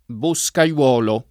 boscaiolo [ bo S ka L0 lo ]